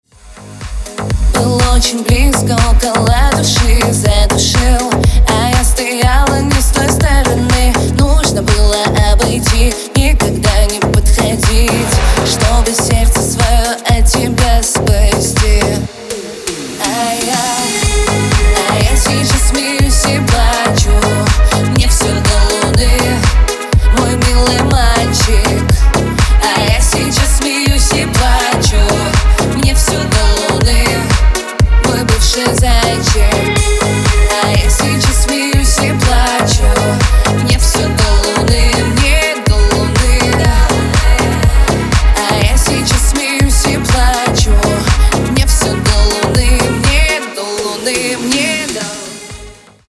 Progressive House / Organic House